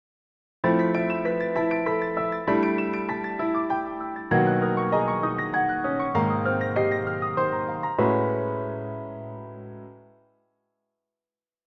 の方はなんか雰囲気良いよねっ！
印象派雰囲気が良い。